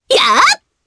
Selene-Vox_Attack4_jp.wav